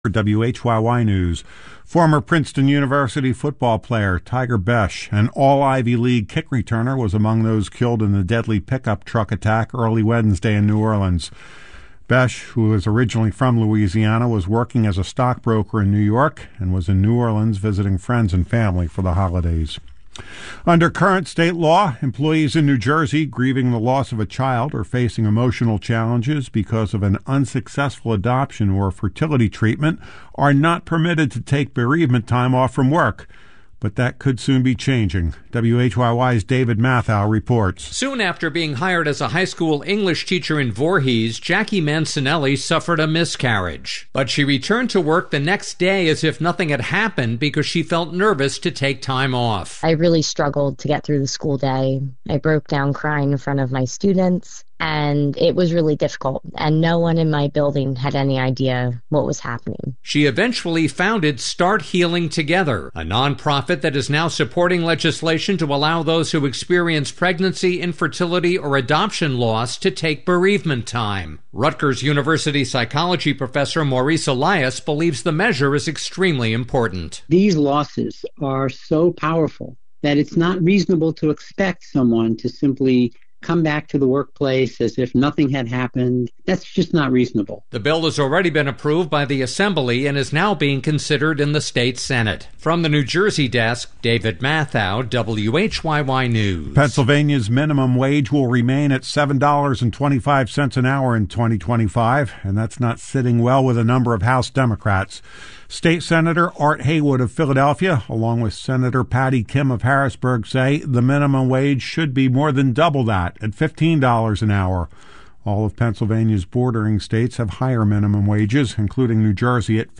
WHYY Newscast